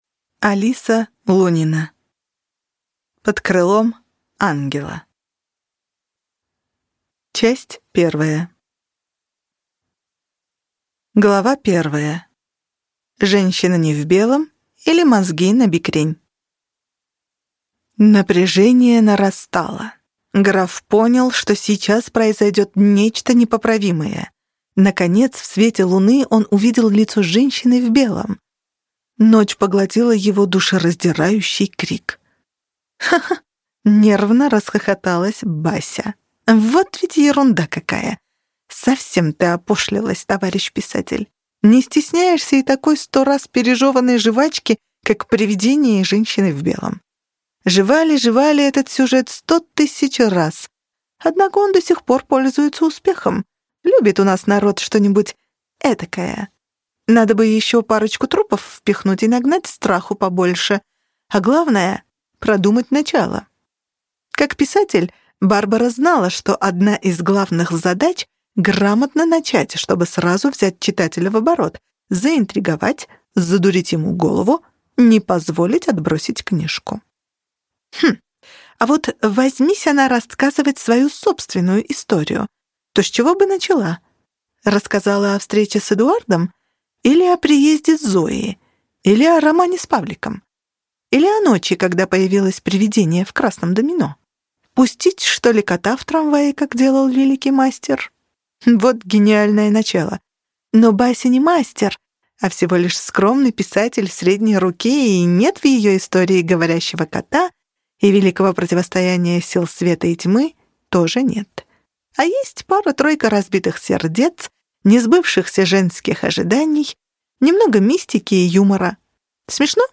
Аудиокнига Под крылом Ангела | Библиотека аудиокниг